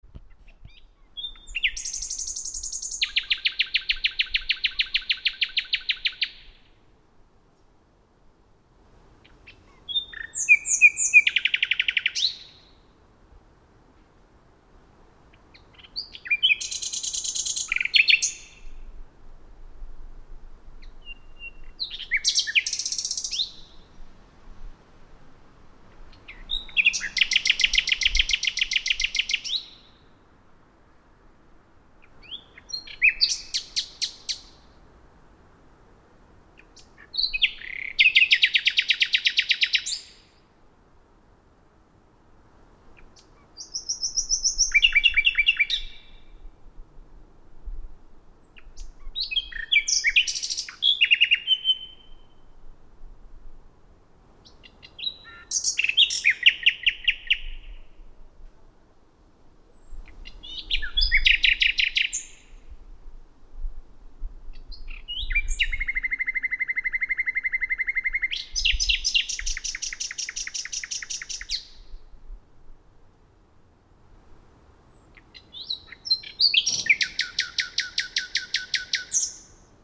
The Nightingale is famous for its melodic song.
The great achievement of the spring is the song of the Nightingale, and yes, they sing throughout the day as well as through the night, apparently immune to fatigue.